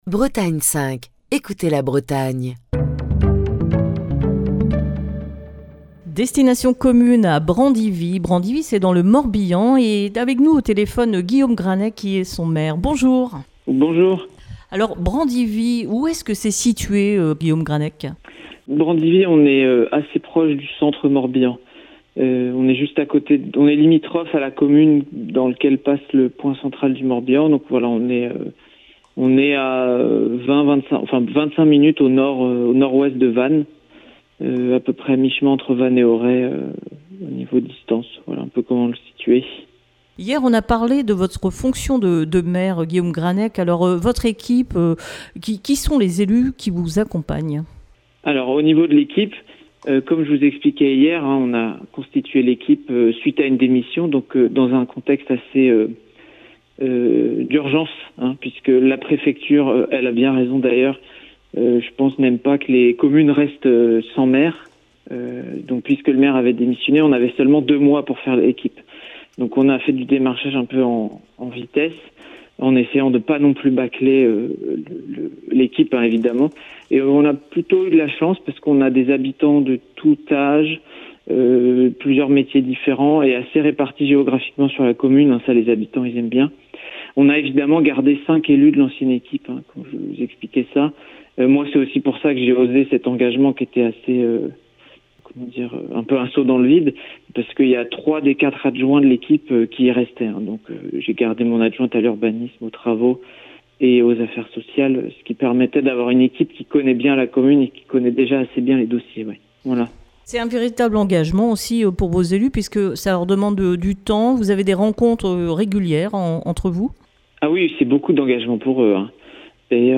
Destination commune fait escale cette semaine à Brandivy dans le Morbihan. Guillaume Grannec, maire de Brandivy raconte sa commune